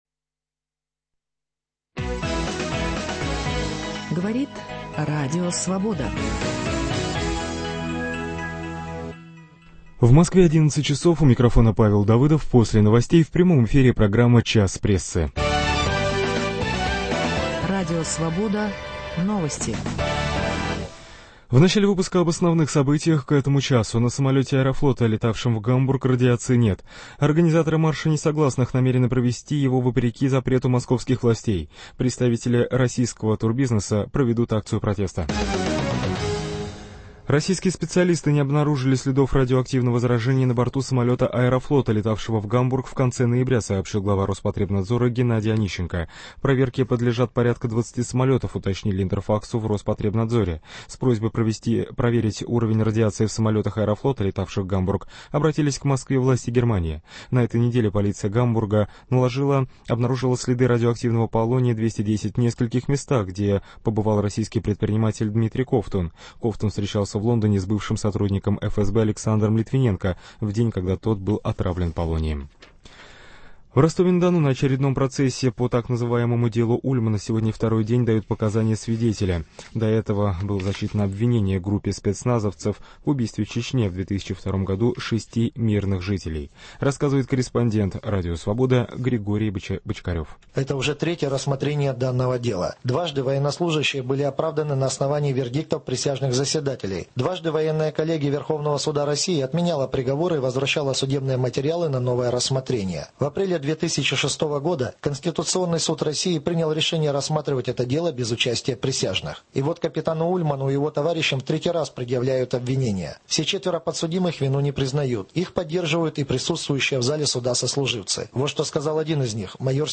А также: зачем "звезды" идут в политику? По телефону интервью с олимпийским чемпионом по фигурному катанию Евгением Плющенко, идущим в Законодательное собрание Петербурга по партийному списку "Справедливой России".